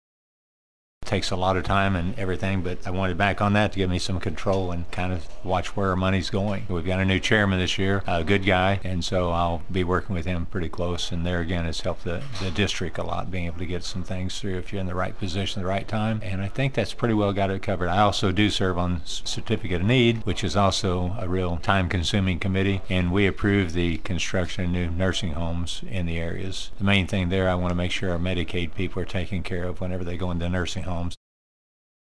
1. Senator Cunningham says he is back on the Missouri Senate Appropriations Committee this year.